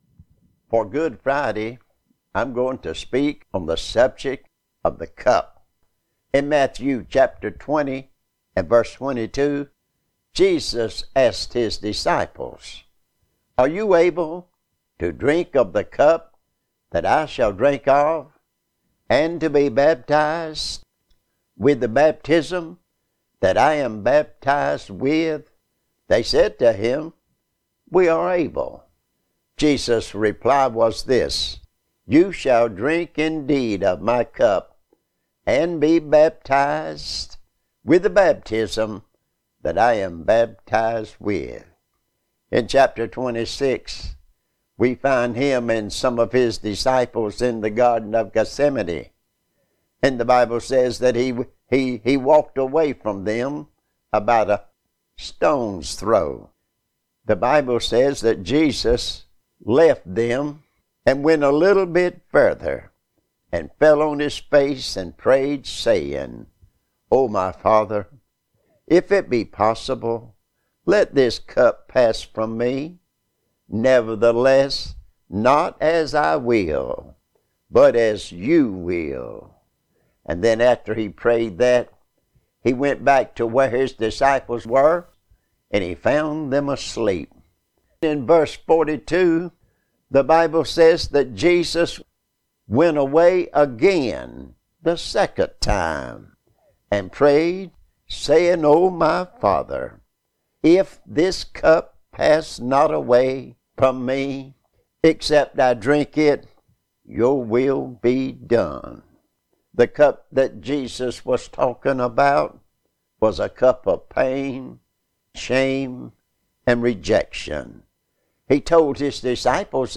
Good Friday Lesson